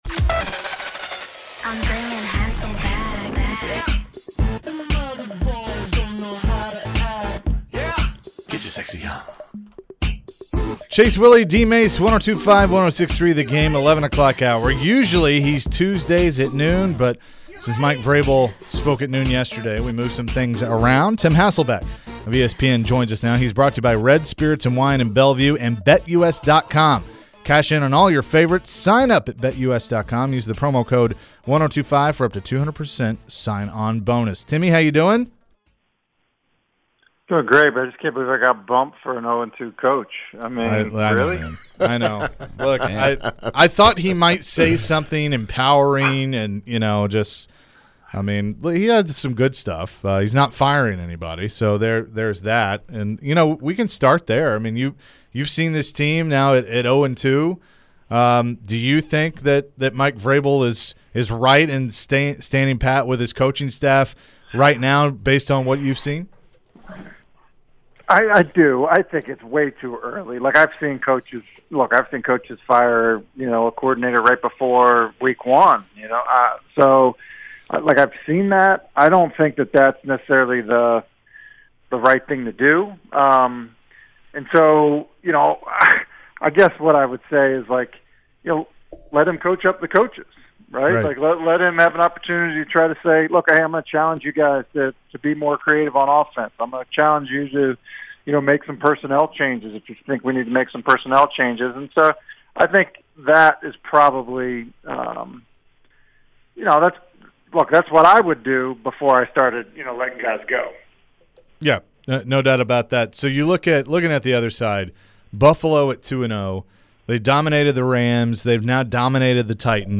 Tim Hasselbeck interview 9-21-22